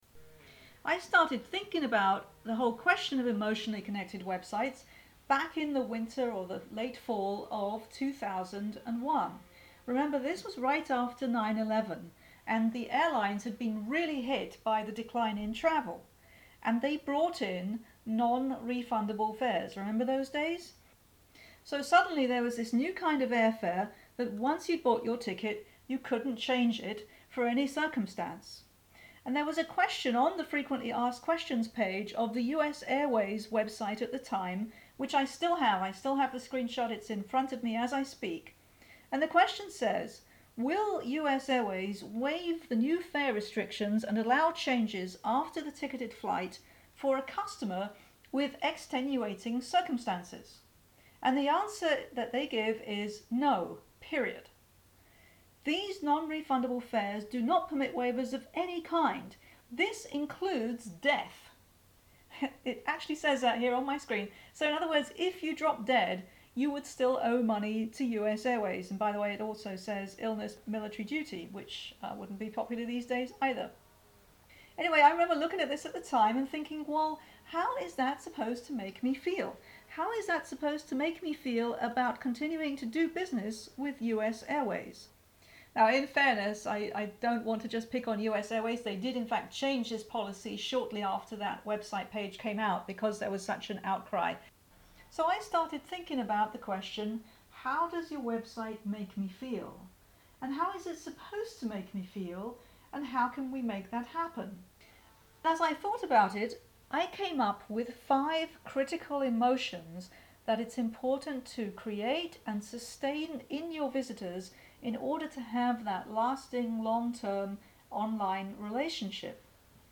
This step-by-step audio guide gives you a complete method for creating and sustaining meaningful emotional connections with your online visitors.